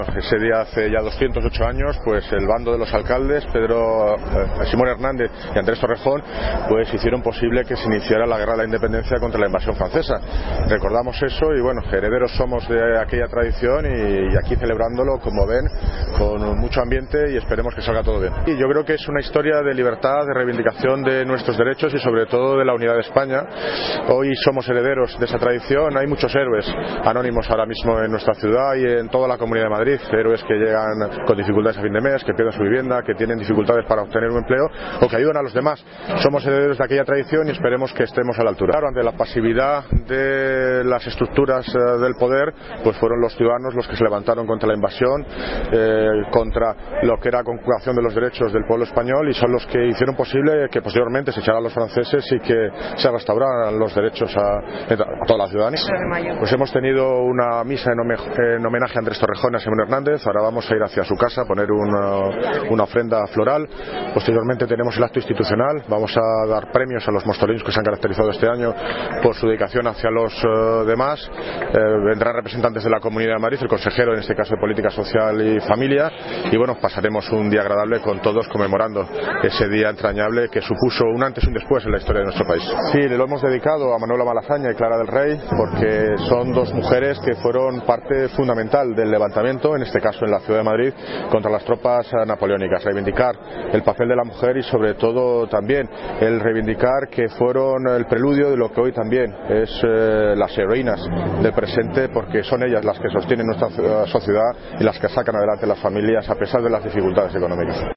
Sonido - David Lucas (Alcalde de Móstoles) Homenaje Andrés Torrejón